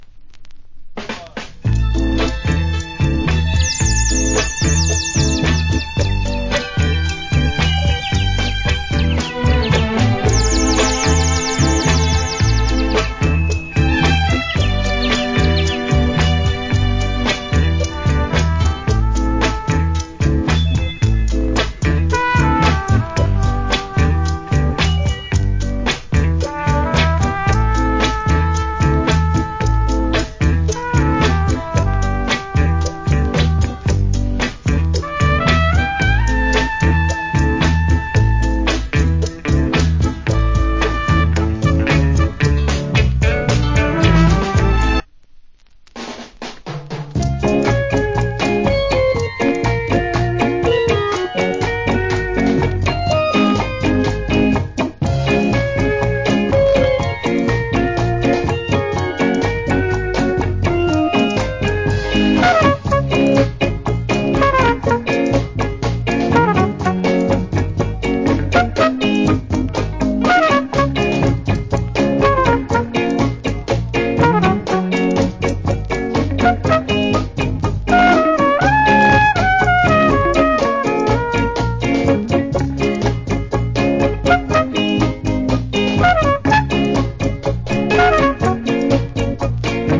Cool Inst.